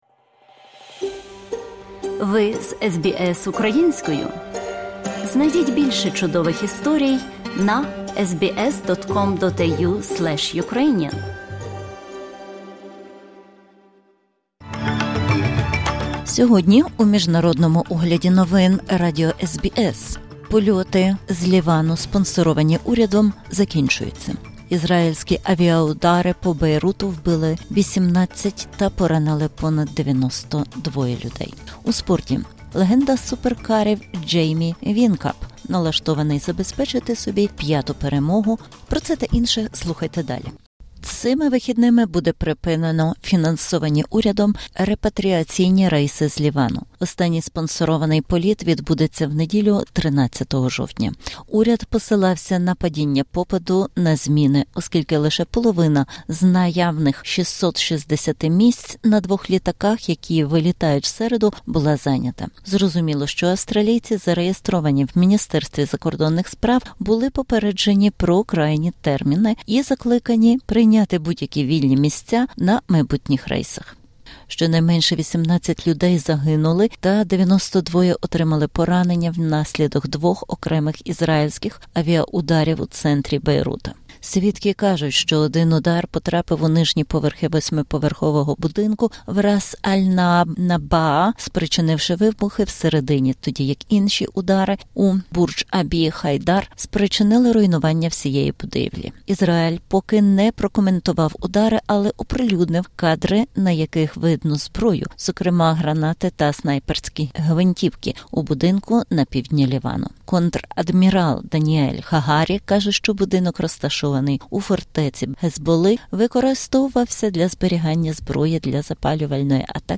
Огляд SBS новин українською мовою.